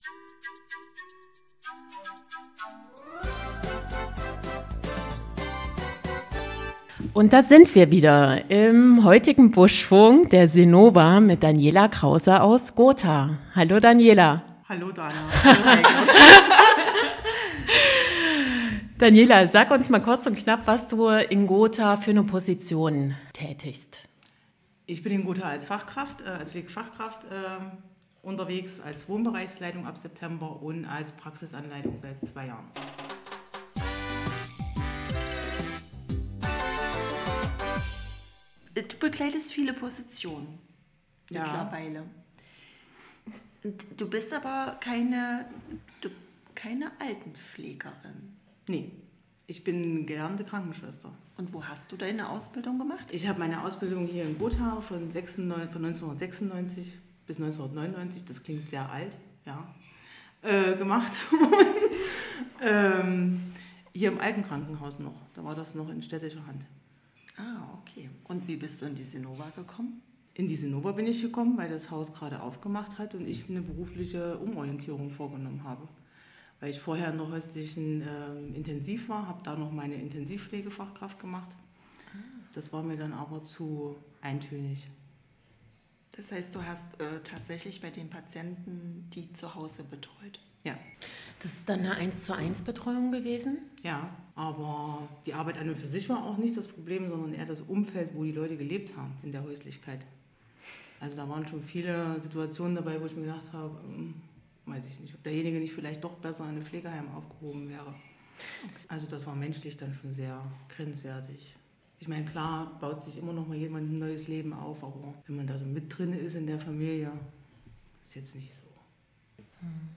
Mit viel Wärme spricht sie über die kleinen Momente, die ihr Kraft geben, und über Herausforderungen, die Mut erfordern. Sie beschreibt, wie wichtig Humor im Alltag ist und warum sie ihren Beruf trotz aller Anstrengungen liebt.
Ein Gespräch, das berührt und zum Nachdenken einlädt.